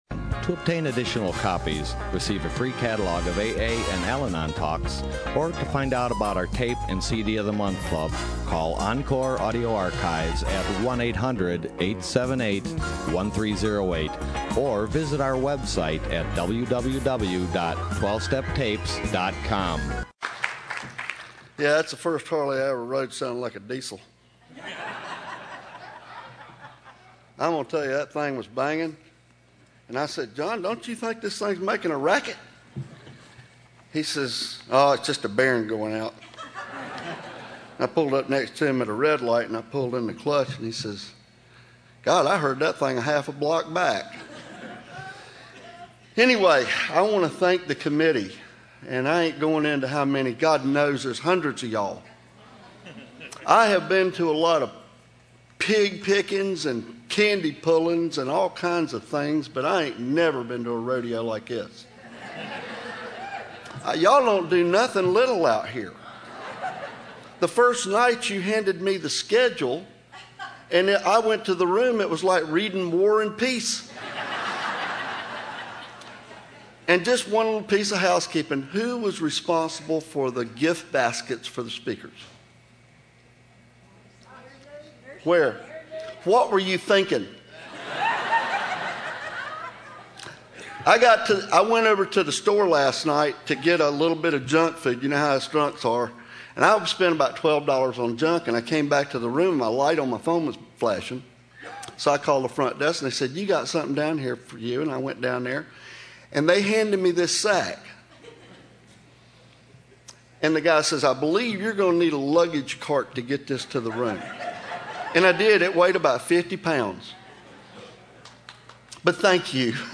SOUTHBAY ROUNDUP 2013
BIKER MEETING &#8211